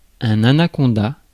Ääntäminen
Synonyymit eunecte Ääntäminen France: IPA: [ɛ̃n‿a.na.kɔ̃.da] Tuntematon aksentti: IPA: /a.na.kɔ̃.da/ Haettu sana löytyi näillä lähdekielillä: ranska Käännös Konteksti Substantiivit 1. анаконда {f} (anakónda) eläintiede Suku: m .